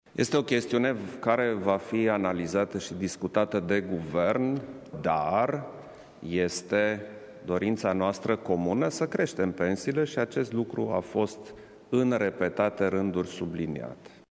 În privința majorării pensiilor cu 40 % din această toamnă, președintele spune că există dorința comună de a crește pensiile:
Iohannis-pensii.mp3